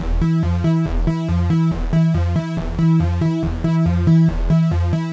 Index of /musicradar/dystopian-drone-samples/Droney Arps/140bpm
DD_DroneyArp3_140-E.wav